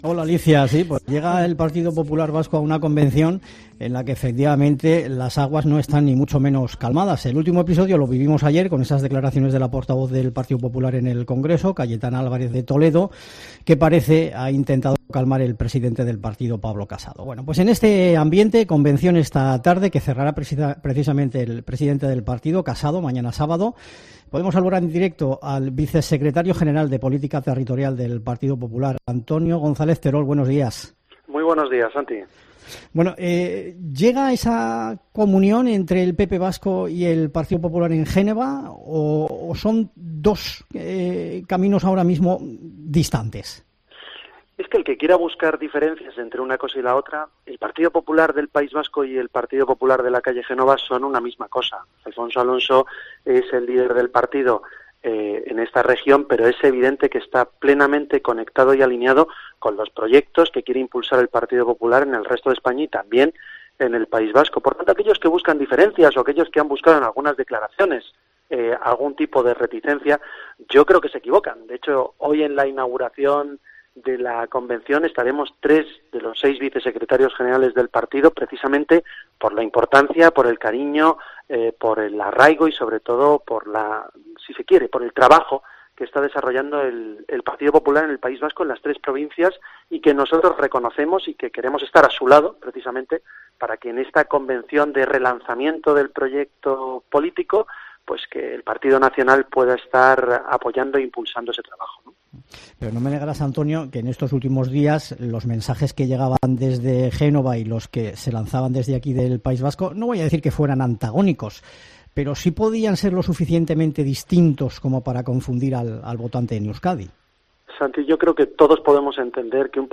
Entrevista a Antonio Gonzalez Terol, Vicesecretario General de Política Territorial del PP